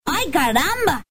детский голос
Забавный короткий рингтон